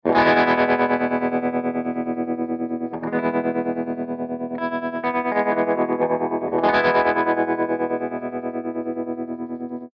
018_AC30_TREMOLO3_P90